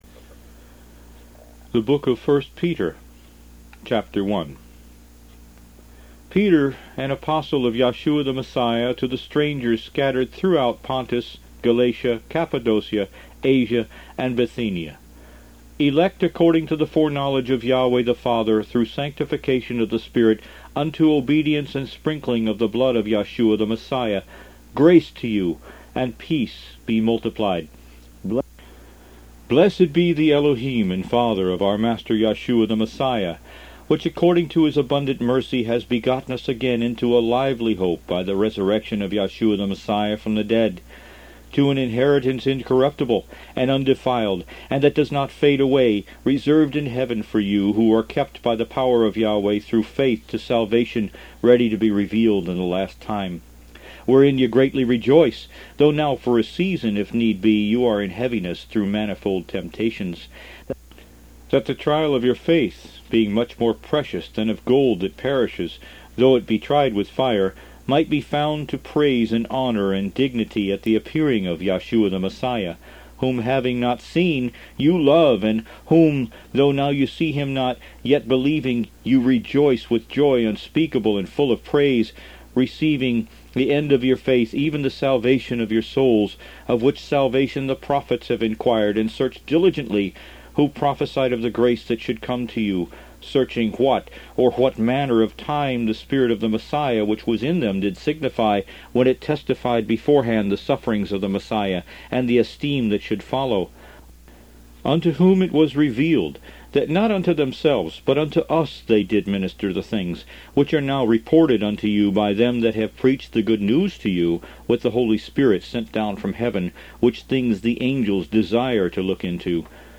Root > BOOKS > Biblical (Books) > Audio Bibles > Messianic Bible - Audiobook > 21 The Book Of 1st Peter